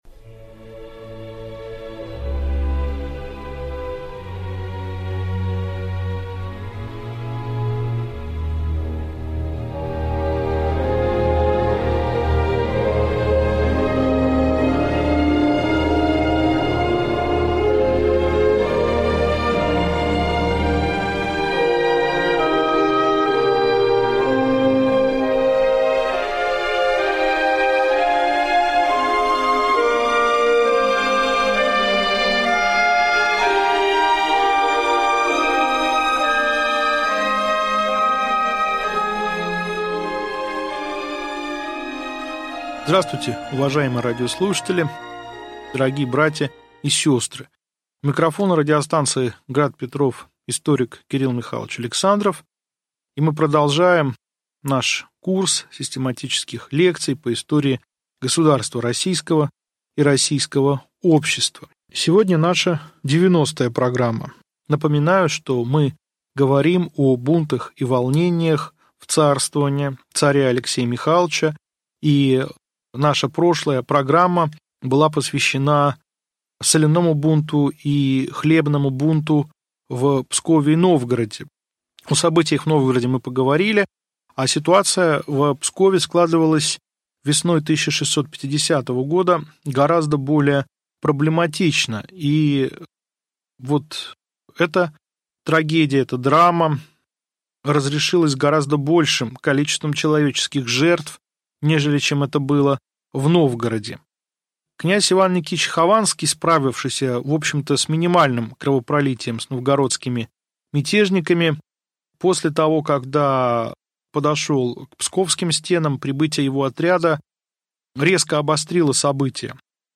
Аудиокнига Лекция 90. Псковское восстание. Медный бунт | Библиотека аудиокниг